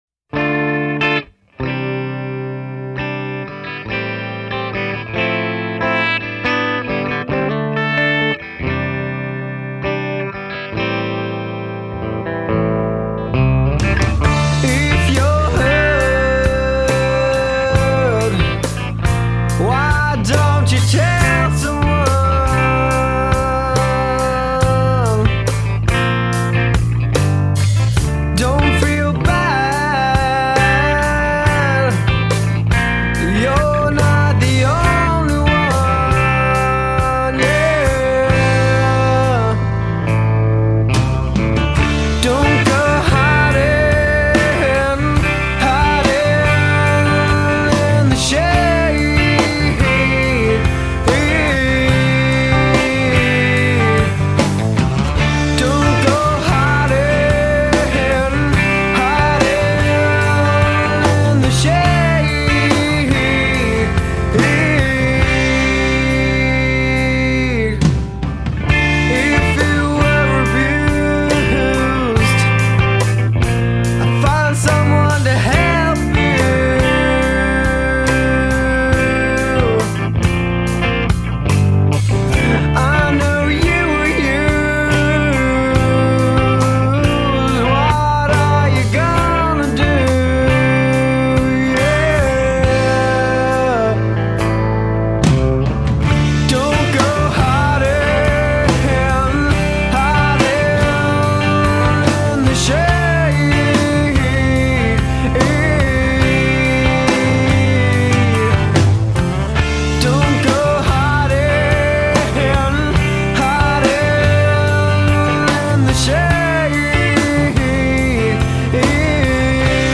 Grunge Rock